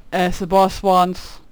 khanat-sounds-sources/_stock/sound_library/voices/voice_acknowledgement/cudgel_ack8.wav at main